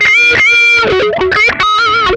MANIC WAH 16.wav